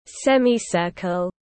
Hình bán nguyệt tiếng anh gọi là semicircle, phiên âm tiếng anh đọc là /ˈsem.iˌsɜː.kəl/.
Semicircle /ˈsem.iˌsɜː.kəl/